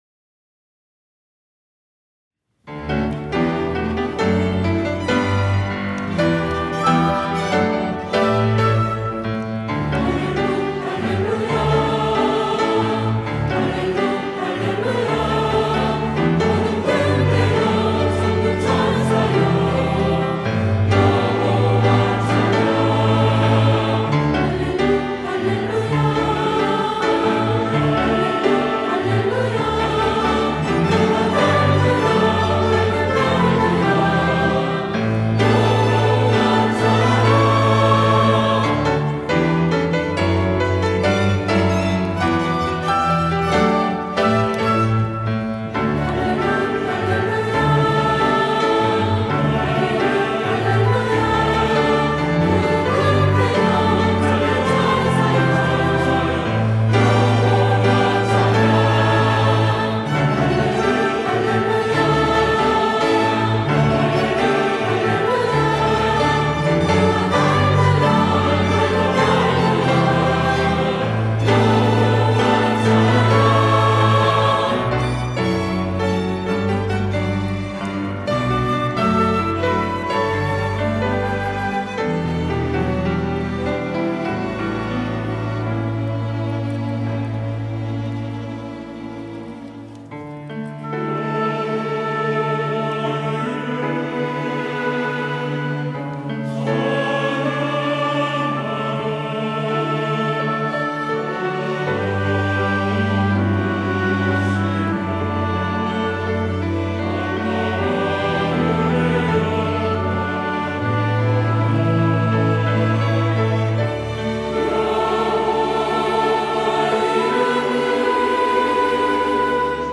호산나(주일3부) - 할렐루야
찬양대